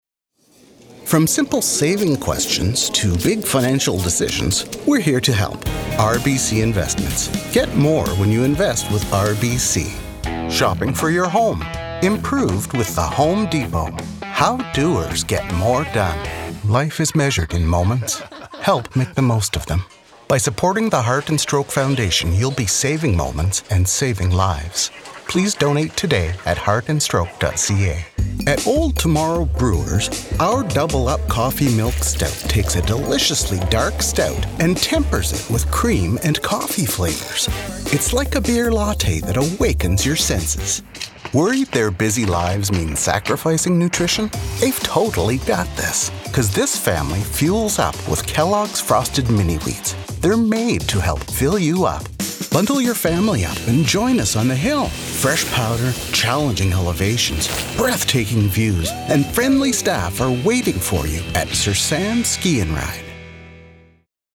Commercial Demo
Insurance Commercial
Canadian; French Canadian; American (various); British (various)
I have my own studio-quality home recording booth (my posted demo was recorded there).
Microphone: Sennheiser MK4 XLR Condenser Microphone
Full-time VO artist - story-teller; explainer; warm; smooth; calm; engaging; trusted advisor; knowledgeable neighbour/friend; spokesperson; genuine; animated explainers; instructor; informative; believable; conversational.